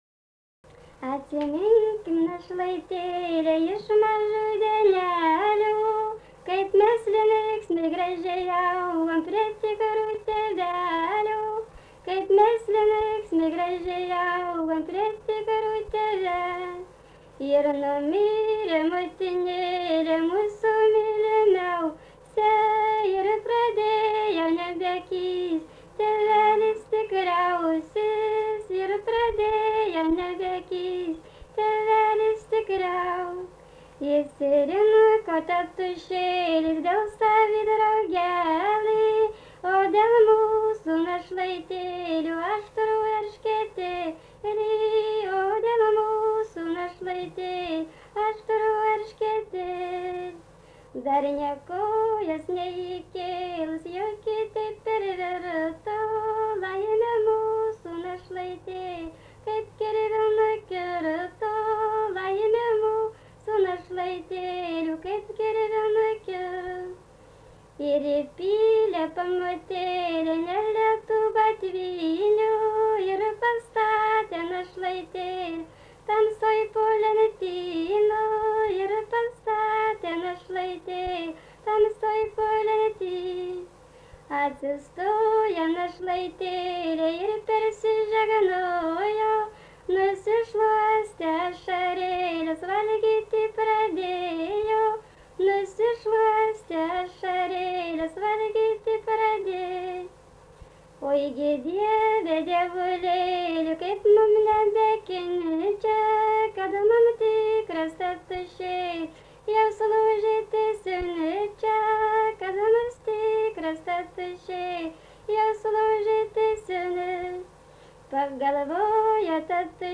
Dalykas, tema daina
Erdvinė aprėptis Jasiuliškiai
Atlikimo pubūdis vokalinis